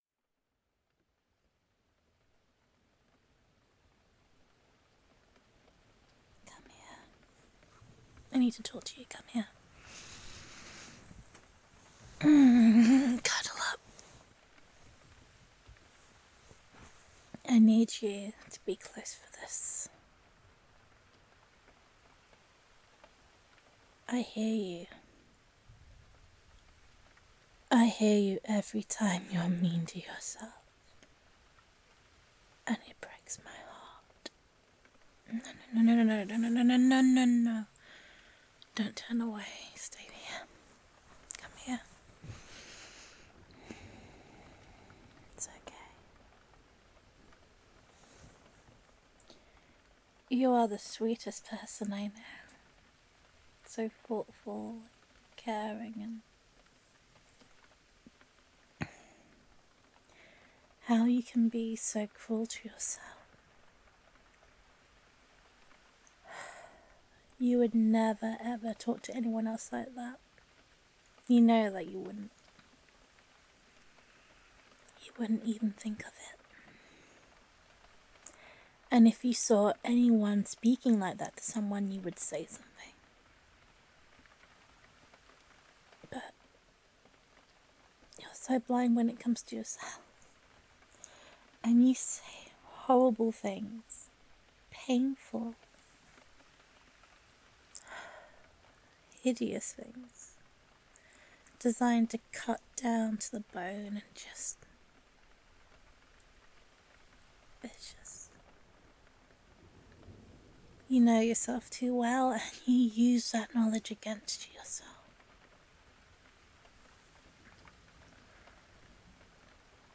Character Type: Supportive Girlfriend
Downloads Download [F4A] You Will See ][Comforting Loving Girlfriend Roleplay].mp3 Content I can hear every cruel word you say to yourself, and it breaks my heart.
Theme or Scenario: Comforting cuddle session after a tough day Emotional Tone or Mood: Warm and Intimate Content Type: Audio Roleplay